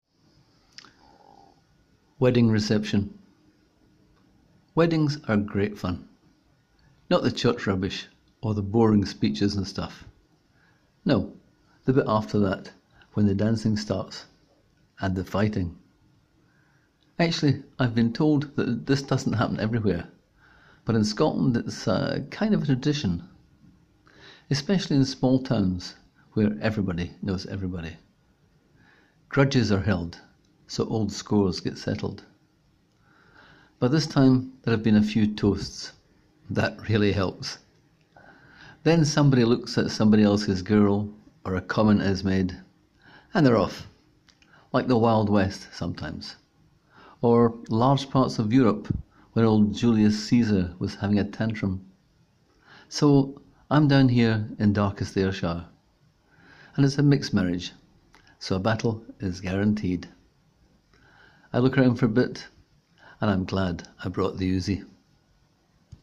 Click here to hear the story read aloud by the author: